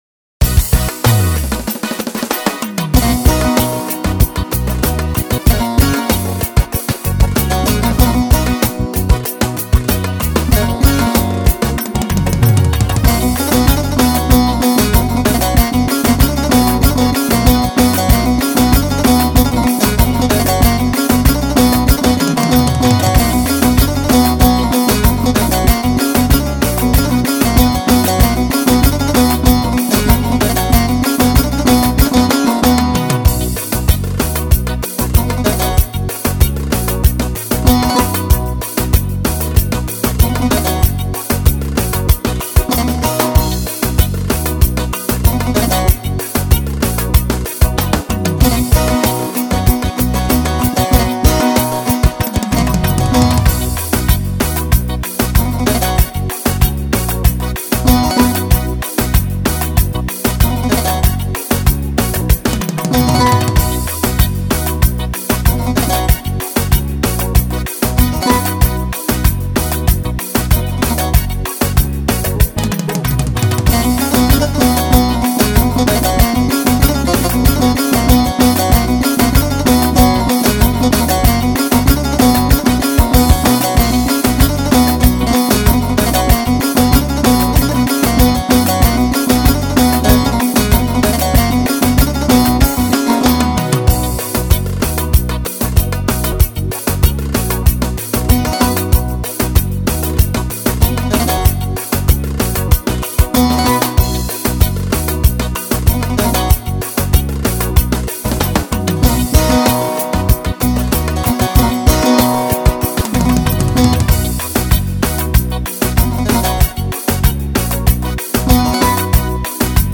ALTYAPI